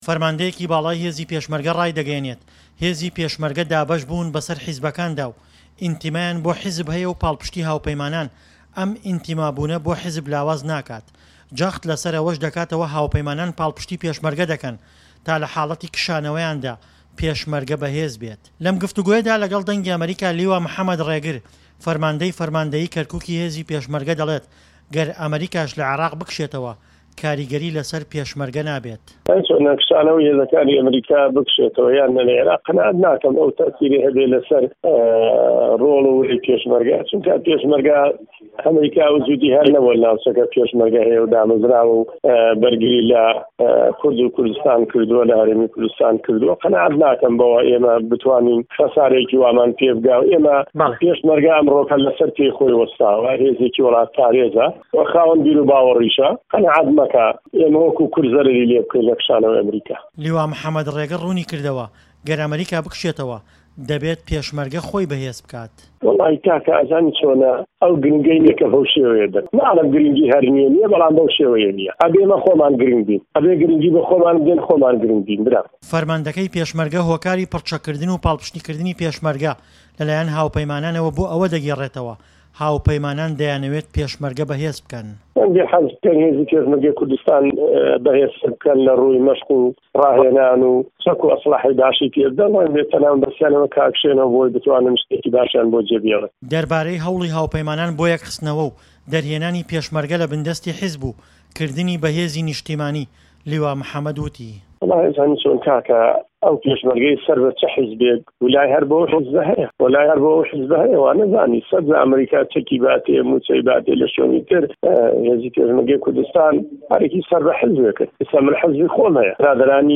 له‌م گفتووگۆیه‌دا له‌گه‌ڵ ده‌نگی ئه‌مه‌ریكا، لیوا محه‌مه‌د ڕێگر فه‌رمانده‌ی فه‌رمانده‌یی كه‌ركوكی هێزی پێشمه‌رگه‌، ده‌ڵێت"گه‌ر ئه‌مه‌ریكاش له‌ عێراق بكشێته‌وه‌، كاریگه‌ری گه‌وره‌ی له‌سه‌ر ڕۆڵ و وره‌ی پێشمه‌رگه‌ نابێت، چونكه‌ پێشمه‌رگه‌ هێزێكی وڵات پارێزه‌ و له‌سه‌ر پێی خۆی ڕاوه‌ستاوه‌."